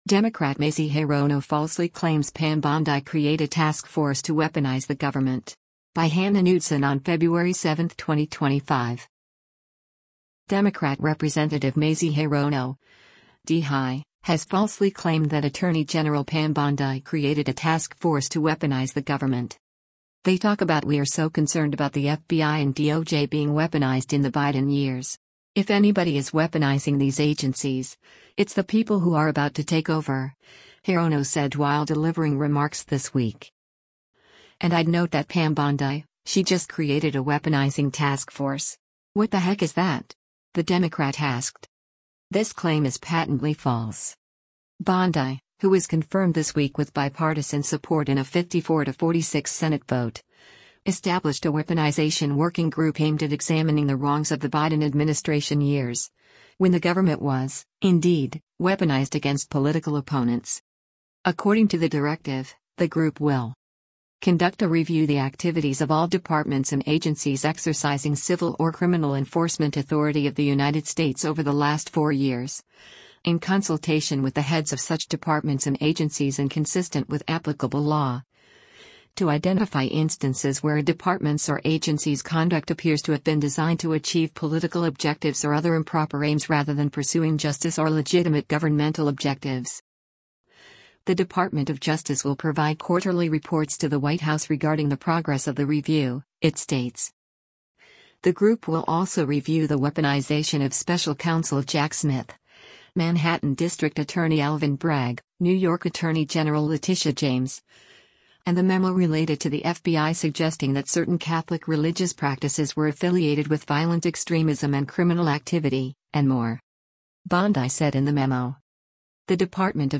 “They talk about ‘We’re so concerned about the FBI and DOJ being weaponized in the Biden years.’ If anybody is weaponizing these agencies, it’s the people who are about to take over,” Hirono said while delivering remarks this week.